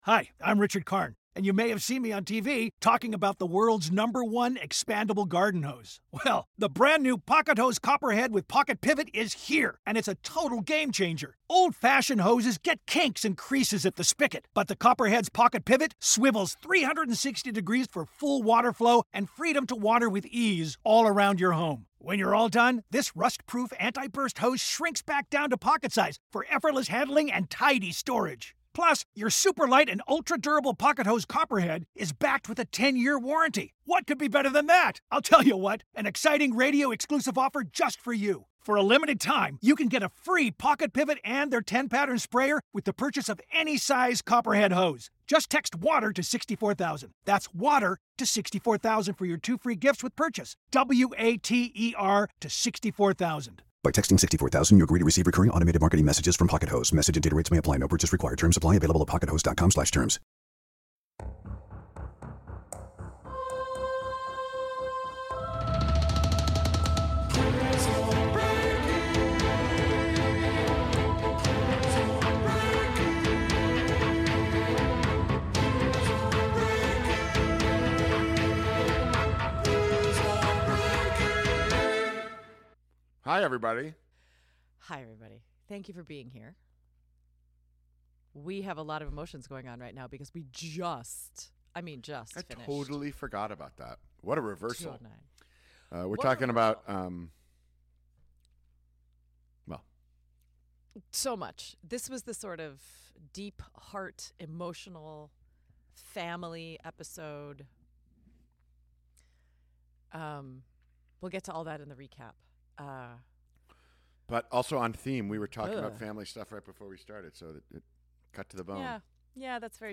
Sarah Wayne Callies (Sara Tancredi) and Paul Adelstein (Paul Kellerman) dig into PRISON BREAK Season 2, Episode 9 "Unearthed". This episode also includes Part 1 of our interview